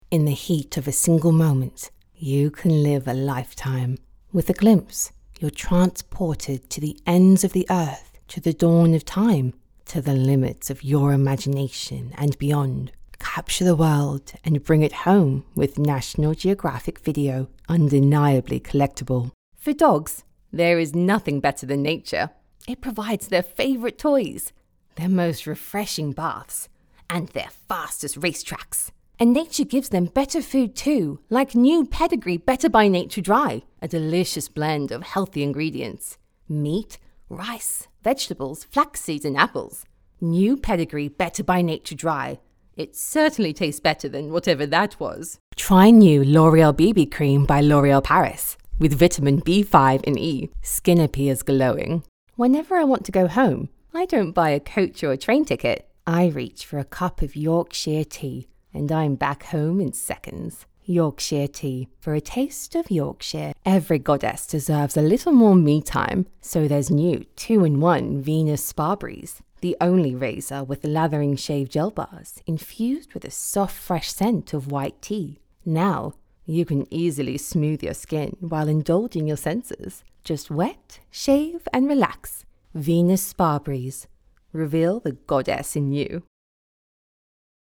Female
Adult (30-50)
Radio Commercials
English (Rp) Commercial Reel
0115English__RP__Voice_Reel.mp3